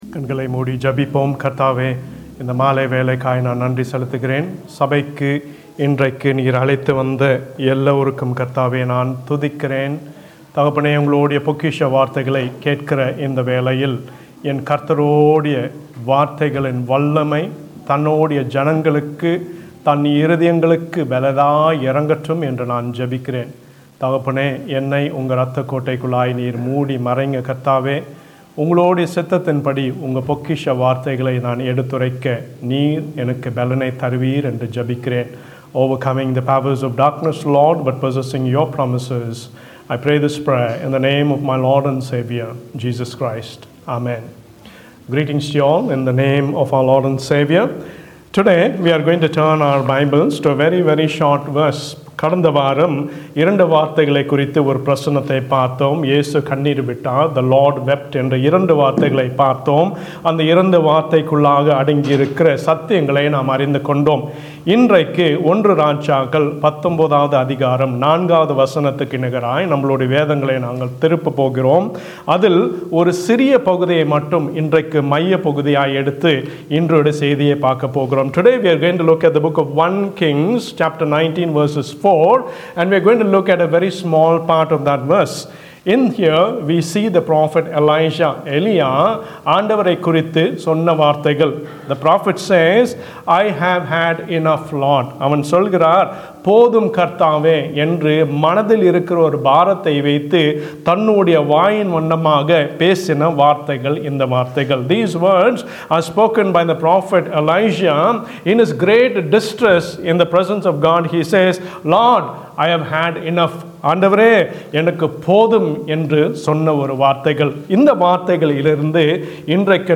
Don't give up - Hope Tamil Church Southampton